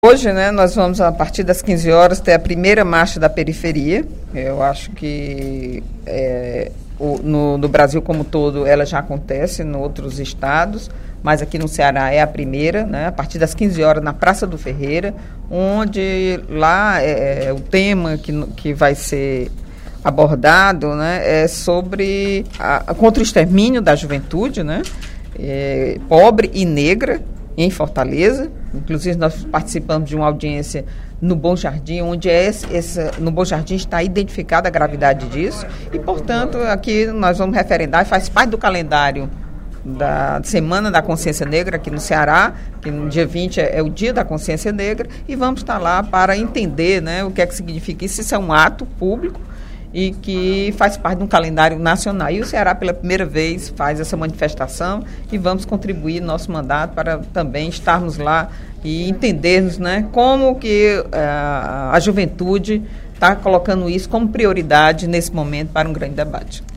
No primeiro expediente da sessão plenária da Assembleia Legislativa desta sexta-feira (22/11), a deputada Eliane Novais (PSB) convidou para a I Marcha da Periferia, a ser realizada hoje, a partir das 15h, na Praça do Ferreira.